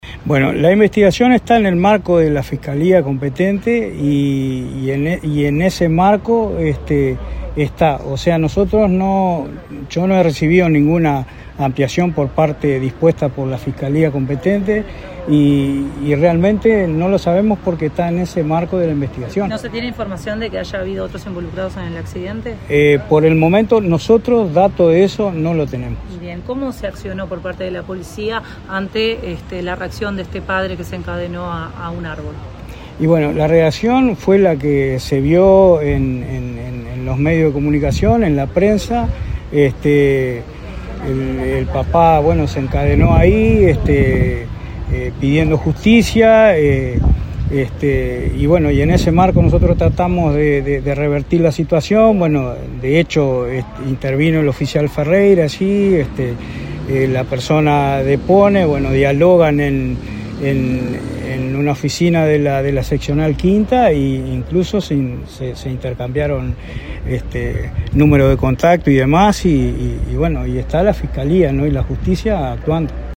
El jefe de Policía, Paulo Costa, dijo este lunes en rueda de prensa que el tema está en manos de la fiscalía.